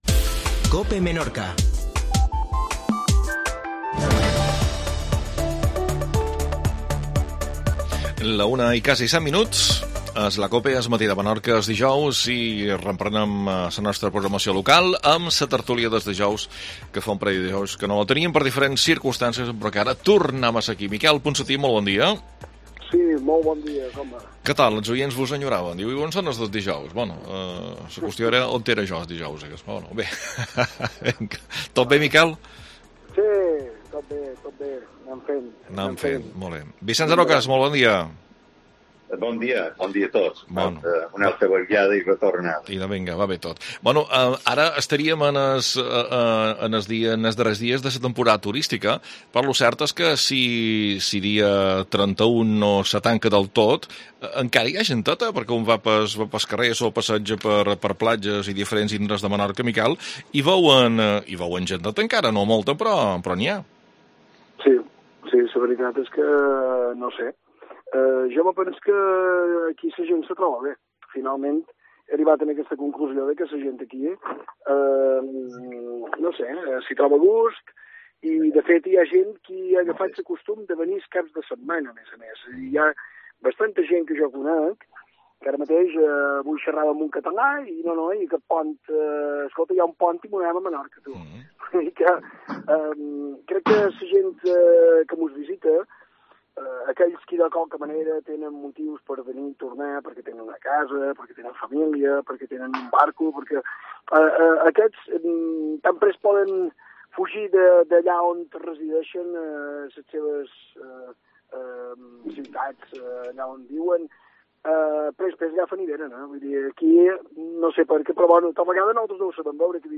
AUDIO: Tertulia